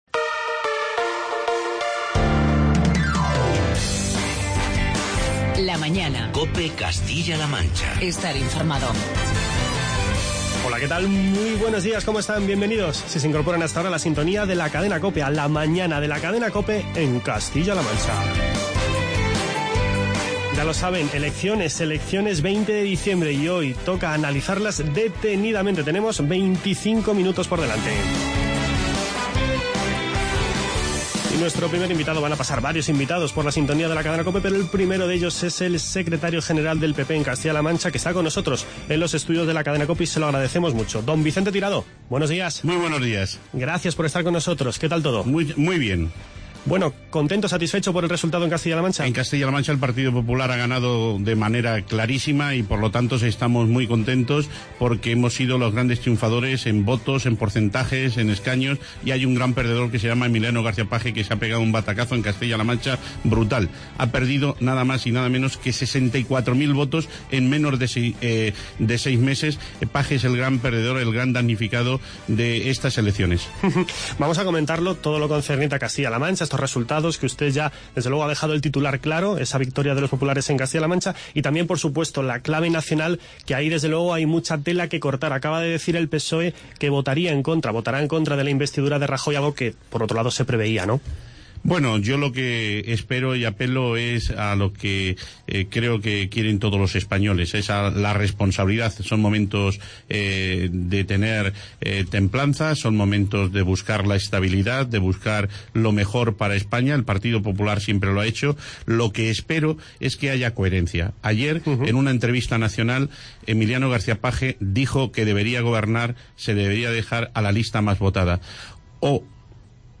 Entrevistas con Vicente Tirado, Cristina Maestre y Esteban Paños.